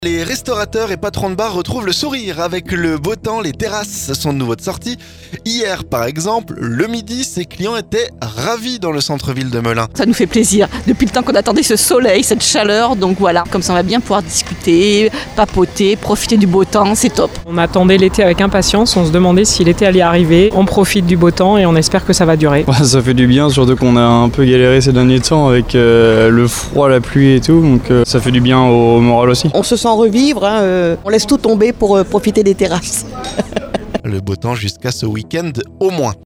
Hier, ces clients étaient ravis dans le centre ville de Melun.